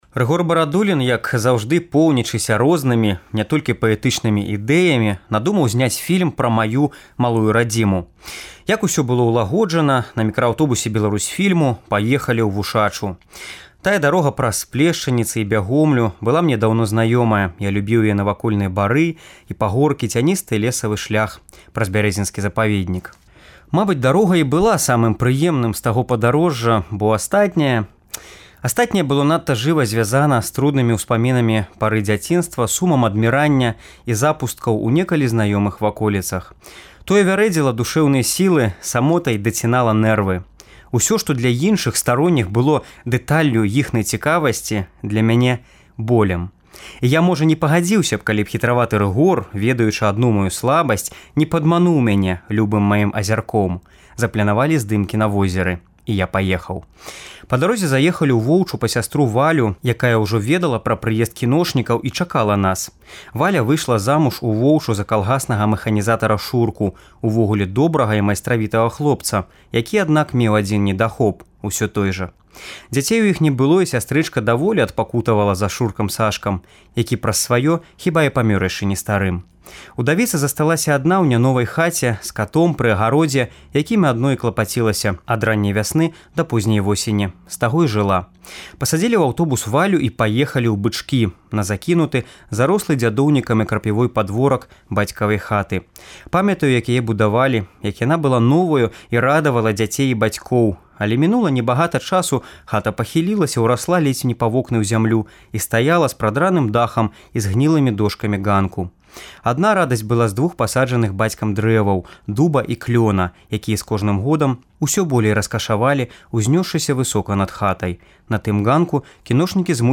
Штодня ў чэрвені выпускнікі Беларускага гуманітарнага ліцэю (цяперашнія і колішнія) чытаюць радкі з улюблёных быкаўскіх твораў.